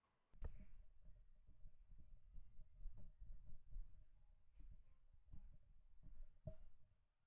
The BEACN Mic is a USB mic.
Yet, with all that moving you’d expect to get a lot of feedback or outside noise as you moved about. However, that’s not the case with the BEACN Mic.
Actual moving about noise